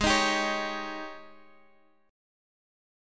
G#M7sus4 chord